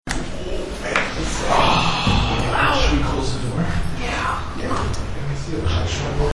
We were all sitting on the floor of the Bug Room in a half circle with the audio recorder sitting two-to-three feet from us in the center.
Then, directly into the microphone there is a loud, breathy voice like an exhale that I believe says "OP-EN UPPPP!"
You can actually hear the pronounced "t" at the end of the second "Get out!"